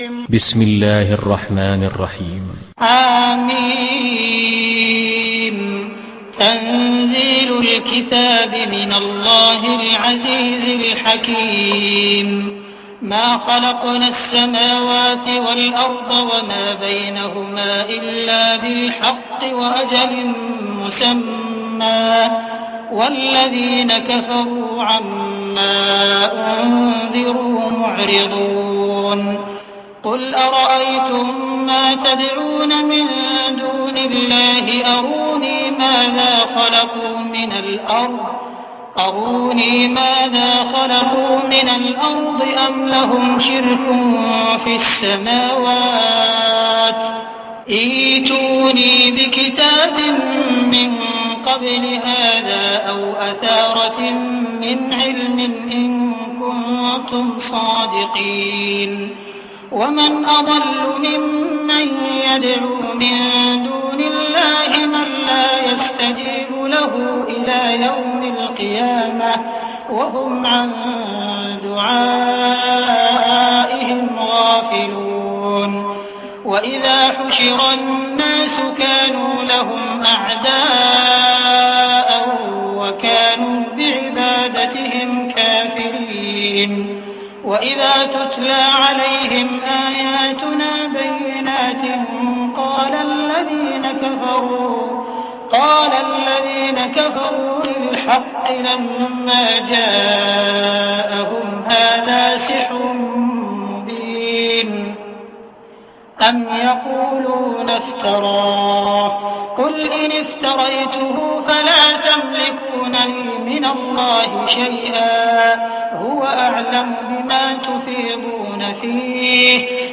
Sourate Al Ahqaf Télécharger mp3 Abdul Rahman Al Sudais Riwayat Hafs an Assim, Téléchargez le Coran et écoutez les liens directs complets mp3